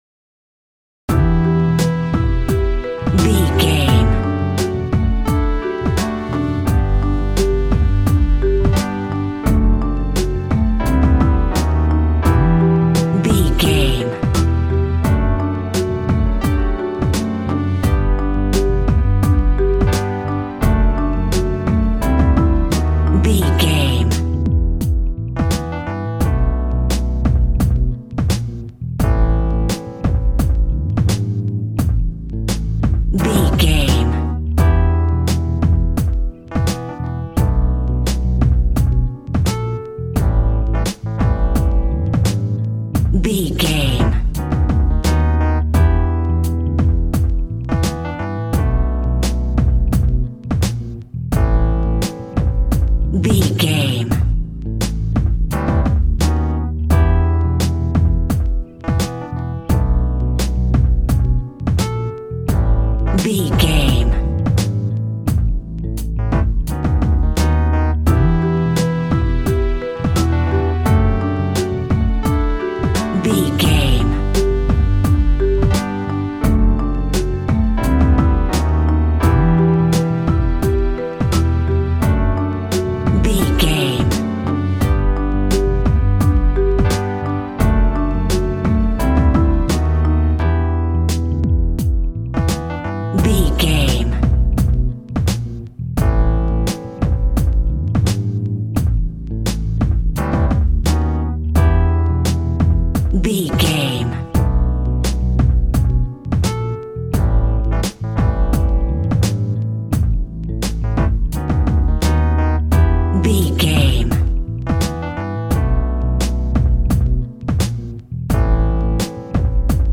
Aeolian/Minor
Funk
electronic
drum machine
synths